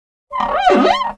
slapstickWipe.ogg